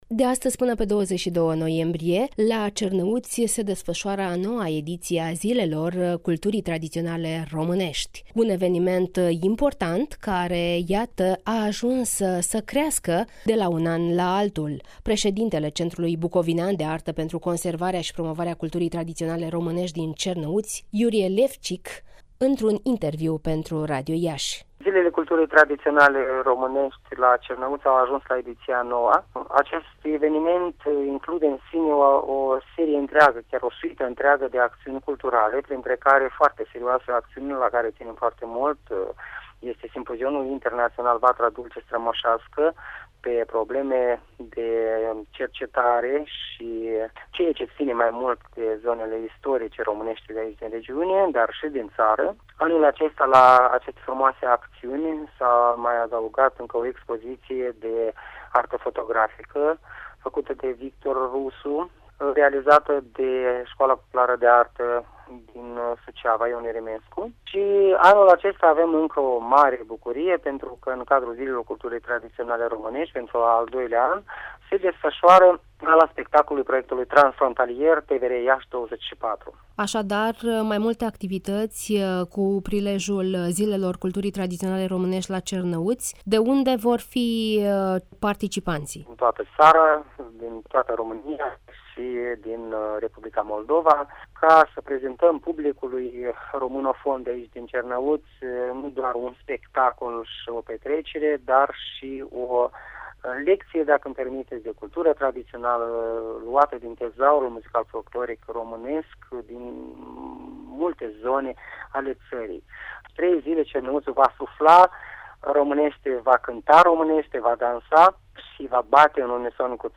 (INTERVIU) Zilele Culturii Tradiționale Românești la Cernăuți